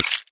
Звук превращения гусеницы в массу и ее раздавливание